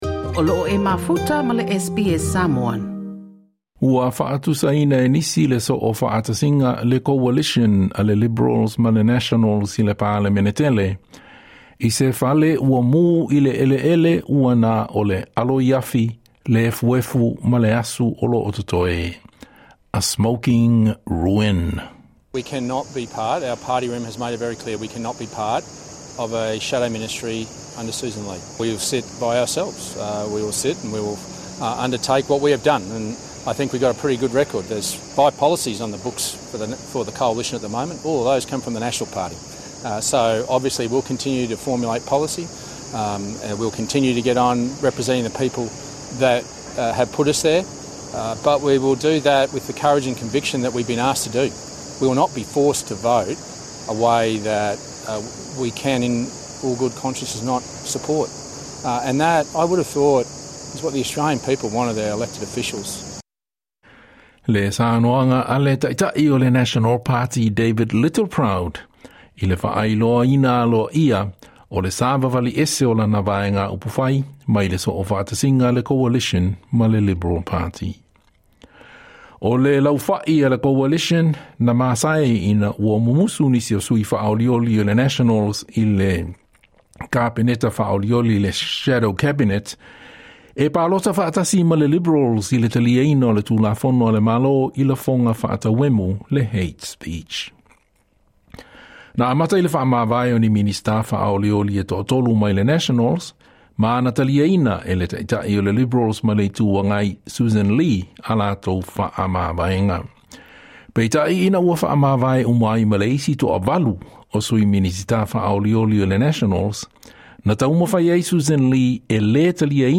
Na saunoa le ta’ita’i o le National Party, David Littleproud, i le fa’ailoaina aloa'ia o le savavali 'ese o lana vaega 'upufai mai le Coalition ma le Liberal Party, e le o toe fia iai lana vaega ‘upufai i se So’ofa’atasiga ae o ta’ita’ia e Sussan Ley le Liberals: